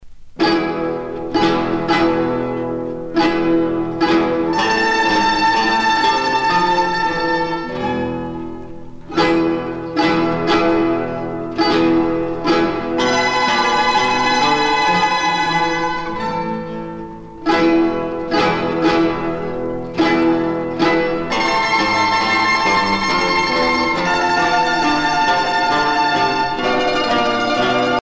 danse : jota (Espagne)